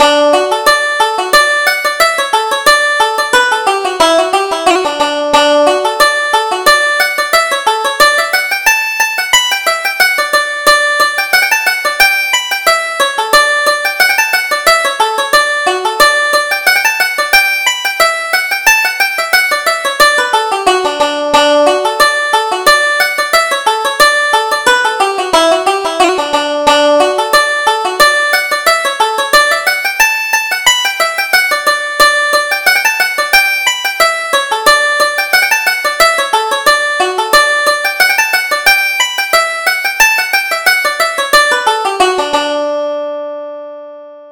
Reel: The Bashful Bachelor